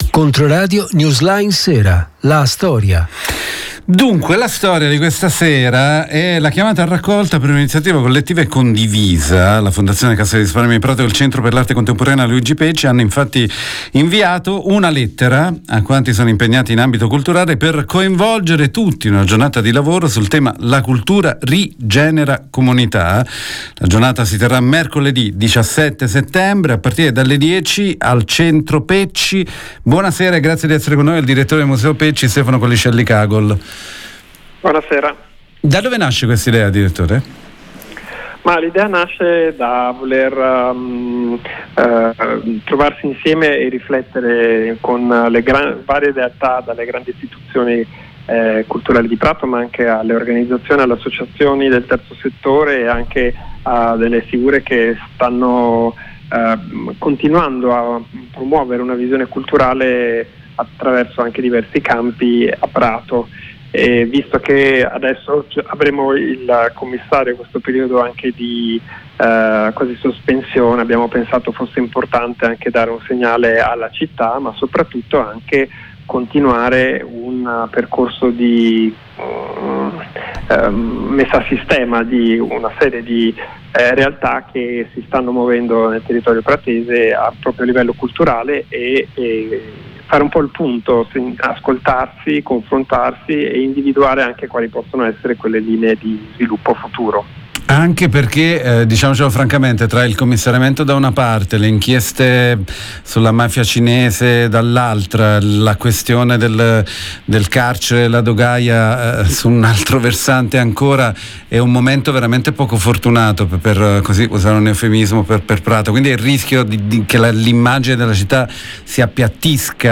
Centro PesciLa Fondazione Cassa di Risparmio di Prato e il Centro per l’Arte contemporanea Luigi Pecci hanno inviato una lettera a quanti sono impegnati in ambito culturale per coinvolgere tutti in una giornata di lavoro, mercoledì 17 settembre a partire dalle ore 10. al Centro Pecci. Abbiamo intervistato